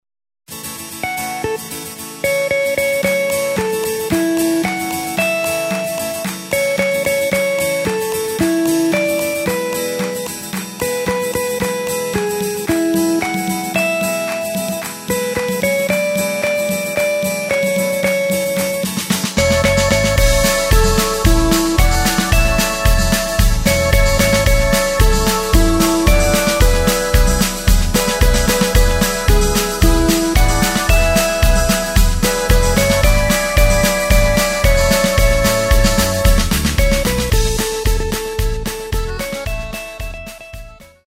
Takt:          2/4
Tempo:         112.00
Tonart:            A
Schweizer Schlager aus dem Jahr 2025!